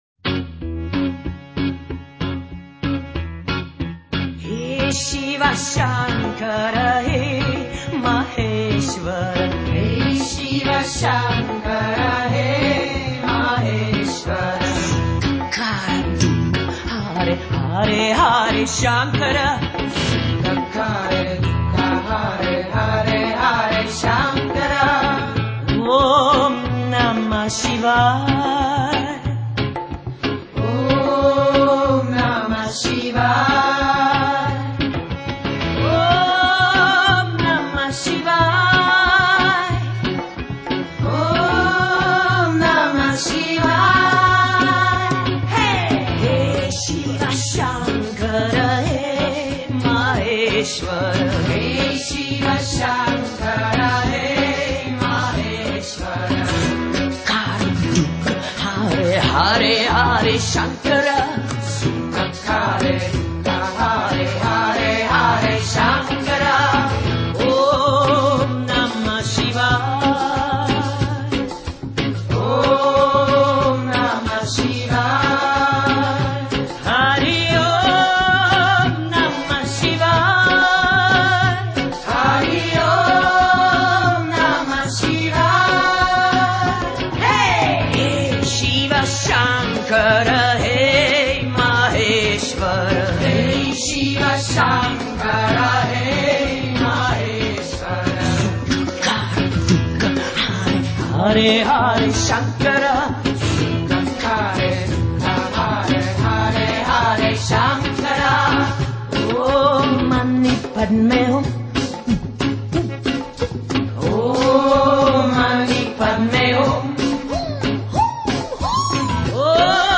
욕처럼 들리는 인도노래^^